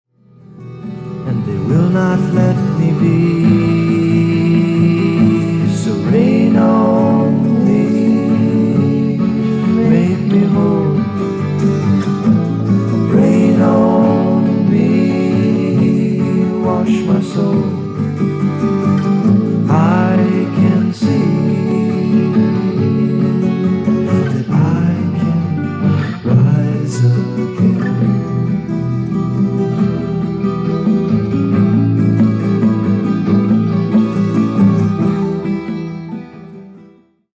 Progressive Rock
デビュー作とは思えないほど、ファンキーかつハードなグルーヴを生み出している。